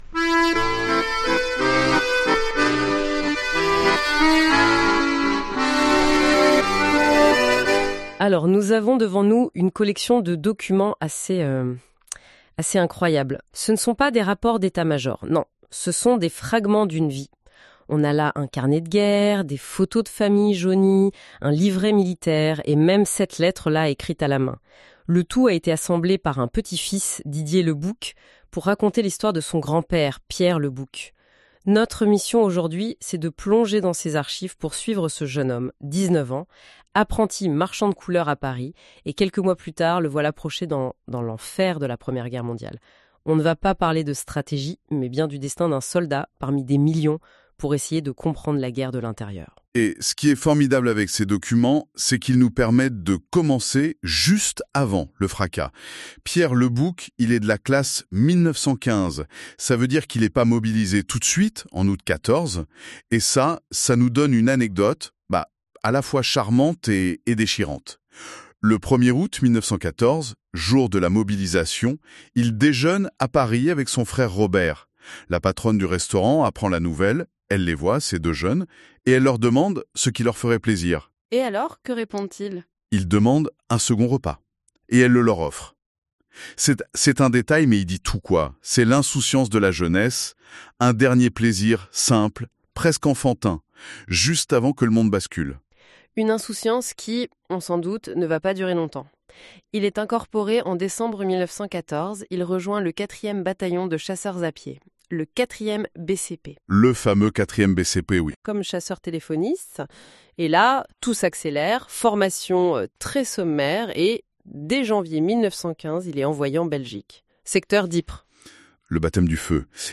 via Google NotebookLLM